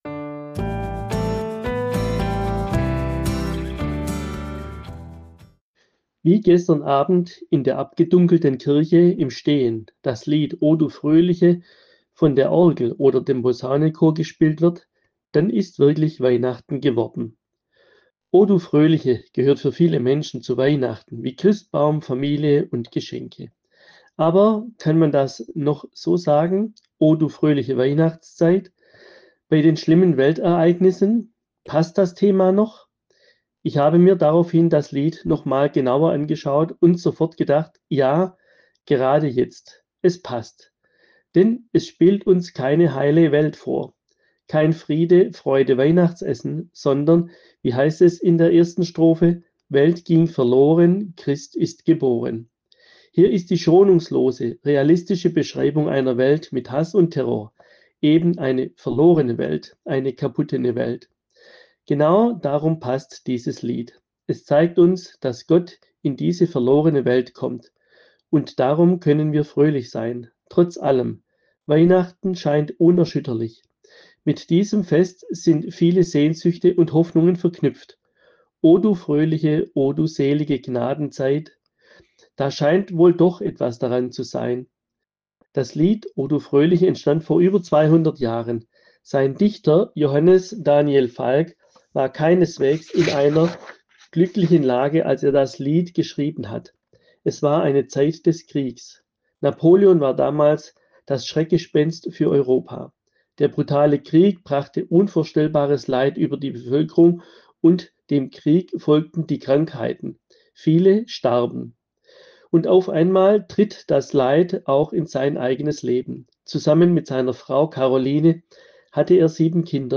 Andacht in der Weihnachtswoche